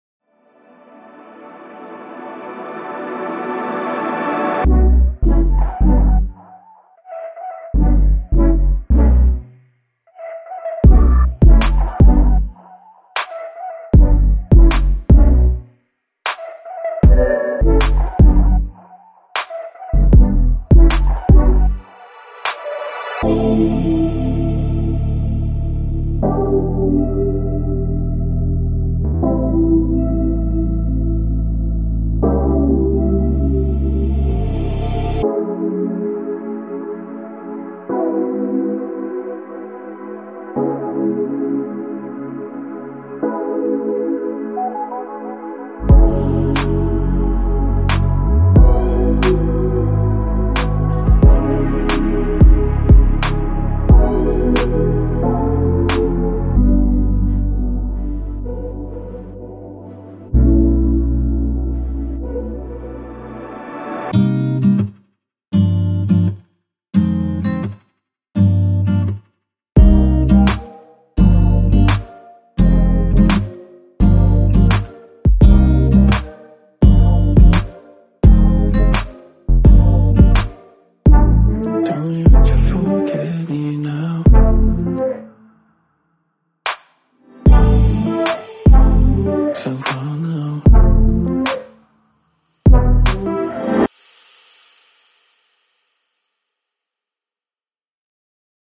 Genre:Soul and RnB
ダークでモダンなR&Bやトラップ向けに106の洗練されたループとワンショットを収録しています。
デモサウンドはコチラ↓